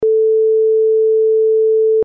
Onda seno a 440 Hz, equivalente a la nota LA 4ª.